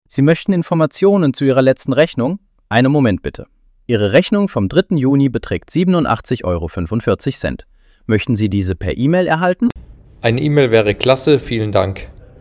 Exzellenter Kundenservice ist unsere Leidenschaft mit den natürlich menschlichen Stimmen unserer Sprachassistenten.
Sprachbeispiel: